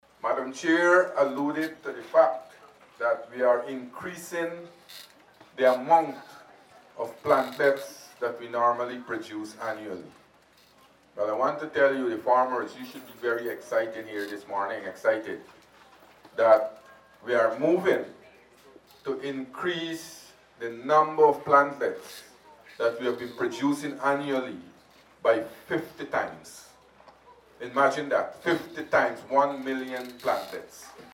Speaking at the commissioning ceremony, Minister of Agriculture Zulfikar Mustapha highlighted that government is making a big push to increase the number of plantlets offered to farmers.